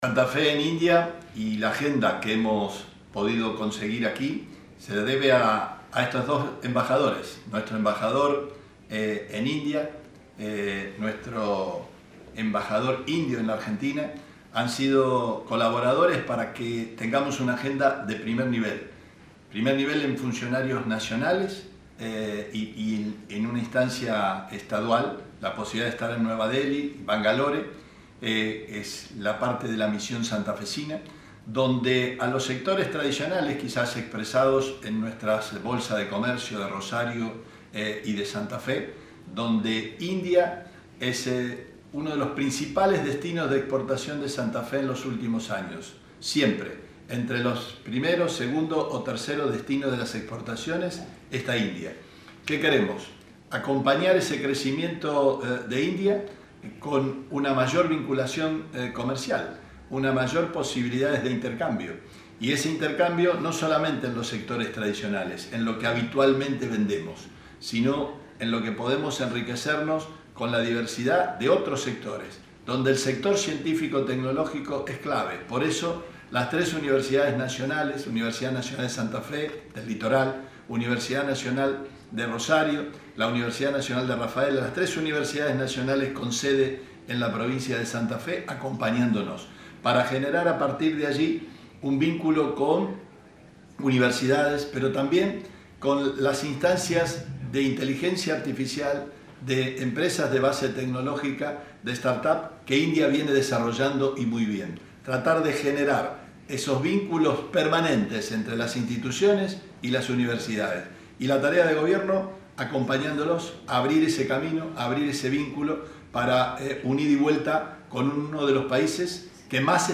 Declaraciones Perotti